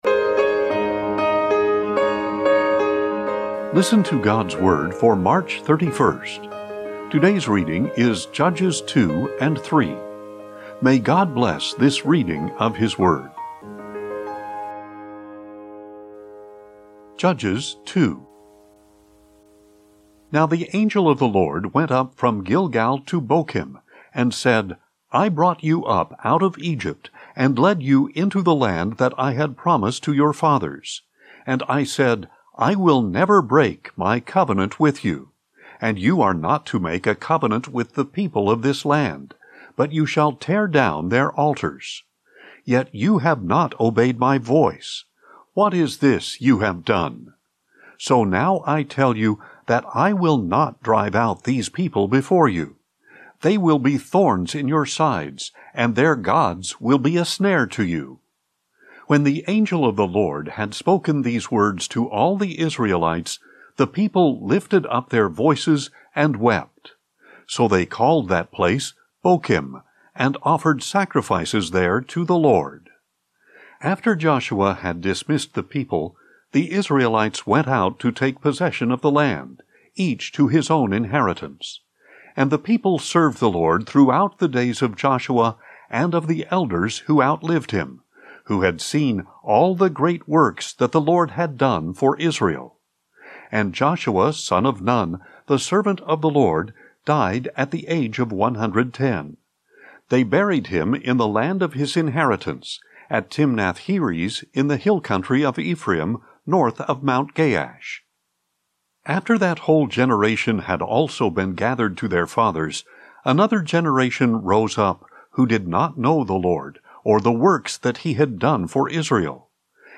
Daily Bible Reading for March 31